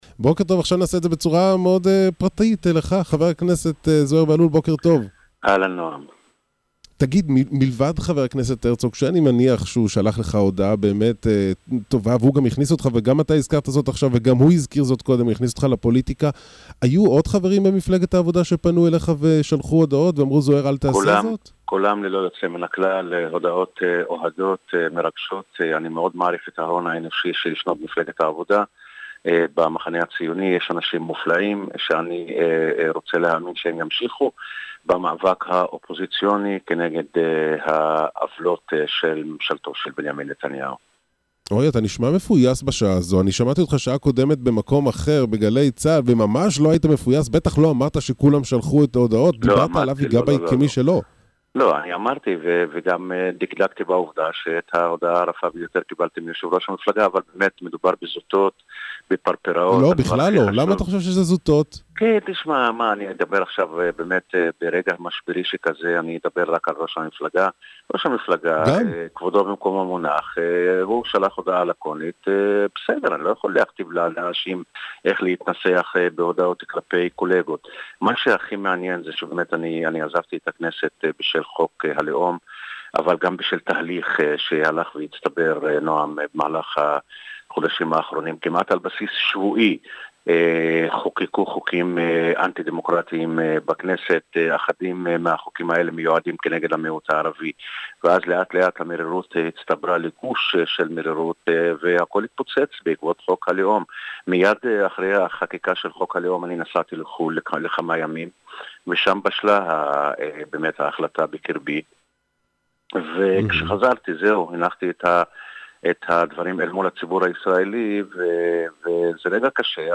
Полностью интервью с Зухайром Бахлулем можно прослушать, перейдя по этой ссылке.